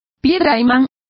Complete with pronunciation of the translation of lodestone.